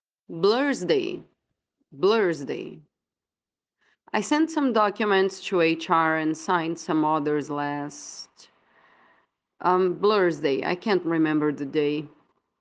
Attention to pronunciation: 🗣